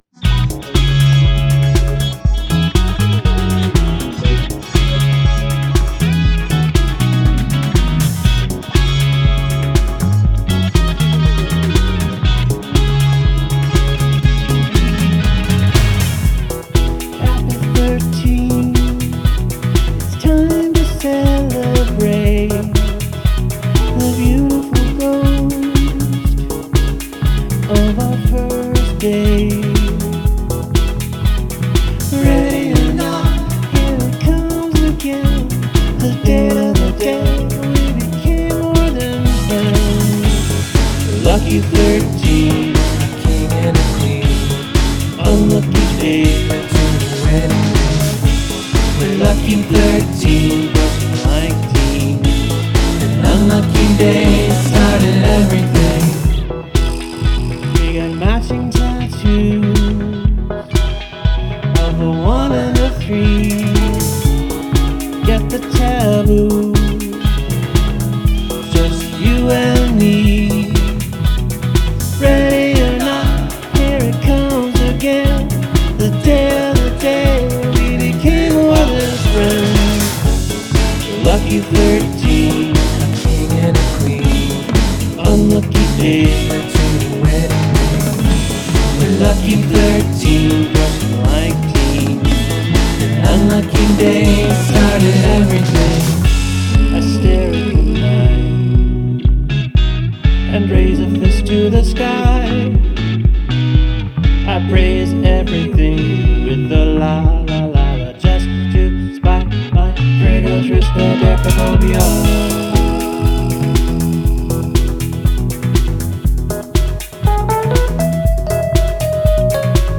write a feel-good song with happy lyrics and upbeat music
:!: :!: :!: :!: :!: Haunted sexy future disco.